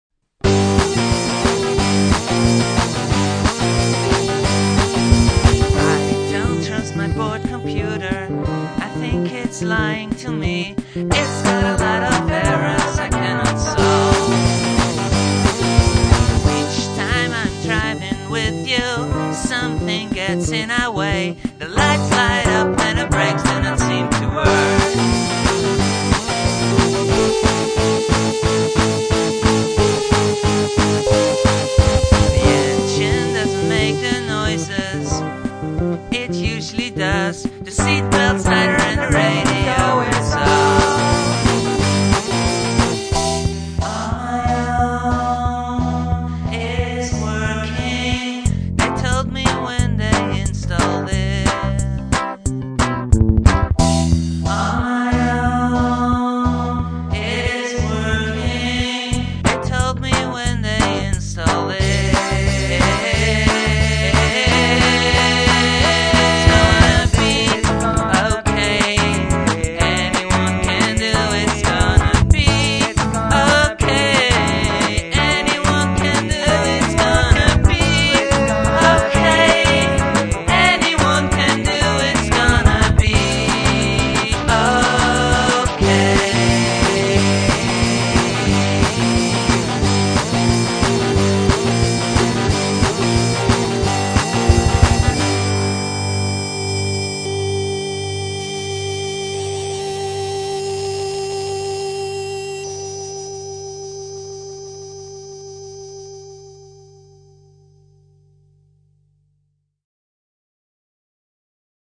trivia: WARNING - 3 parts in 2 minutes